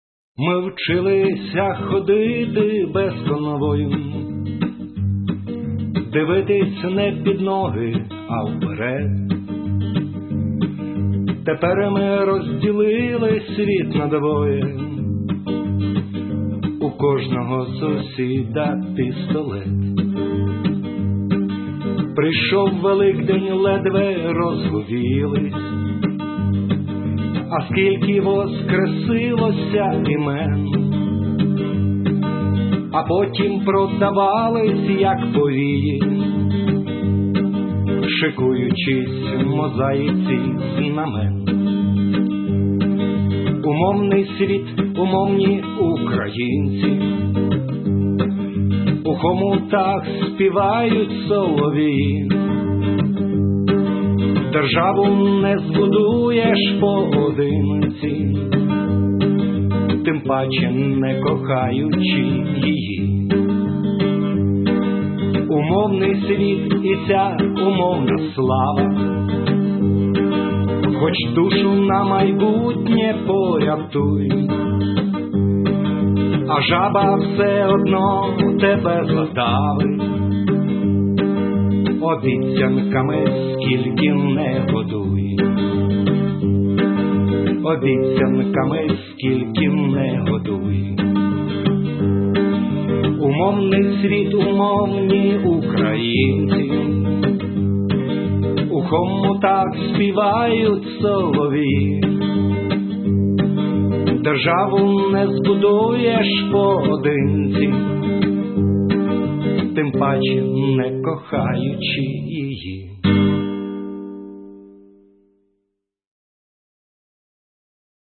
Бардівські пісні